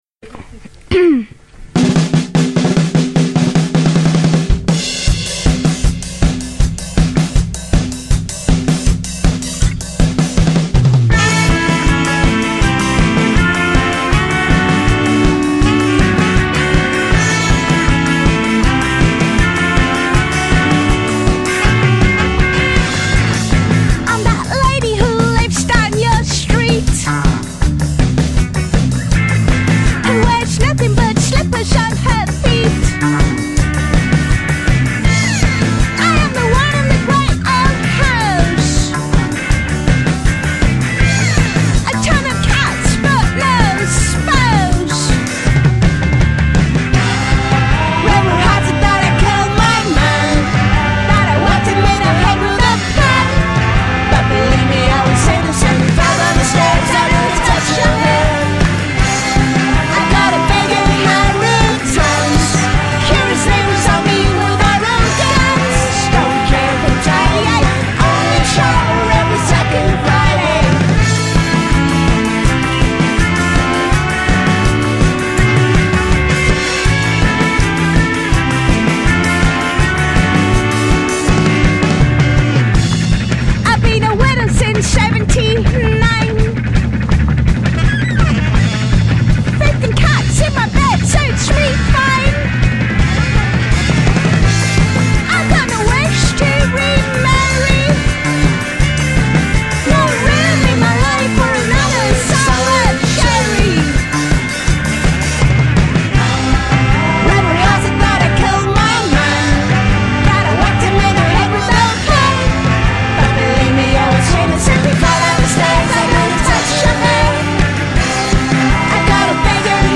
una versione demo